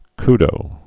(kdō, ky-)